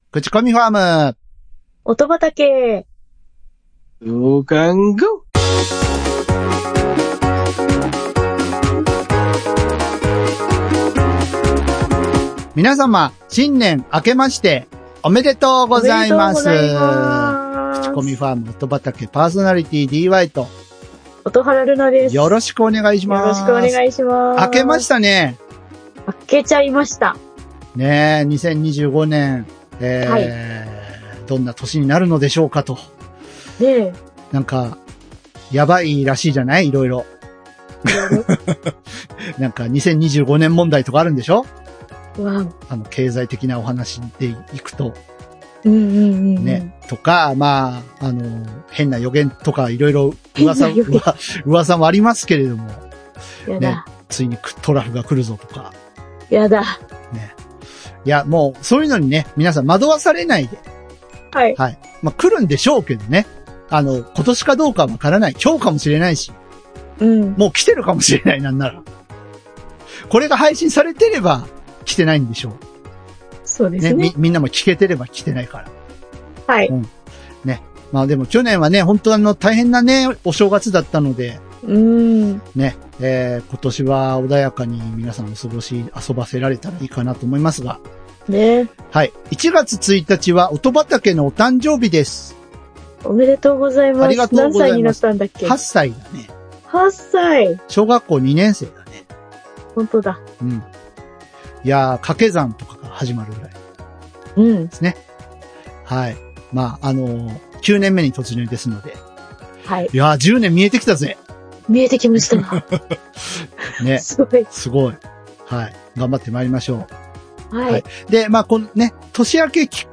音楽ファンの九州人２人が、毎回一つのテーマに沿って曲を紹介しあうことで良質な音楽を口込んでいく音楽紹介プログラム。